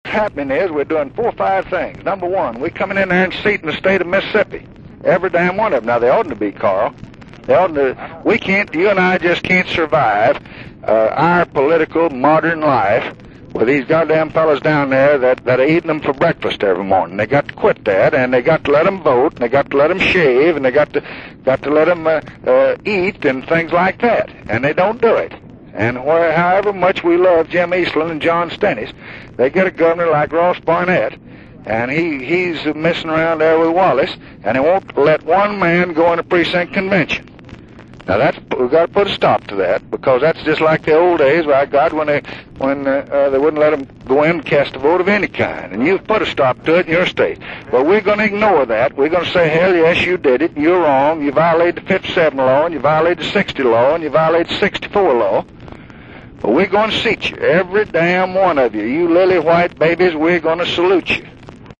The President made clear his displeasure to Georgia governor Carl Sanders, in one of his most emotional calls of the campaign season.